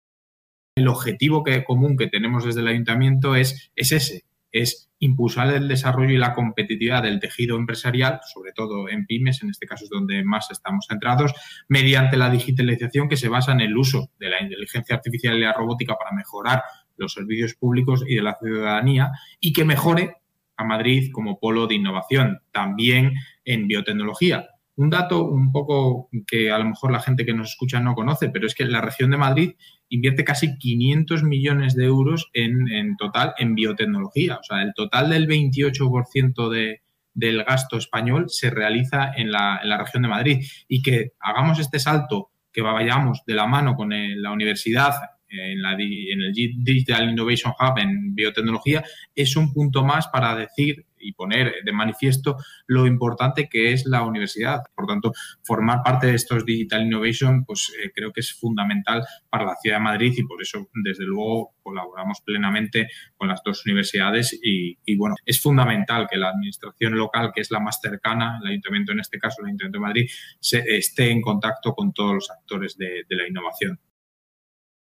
Nueva ventana:Intervención de Ángel Niño en la Open Expo Virtual Experience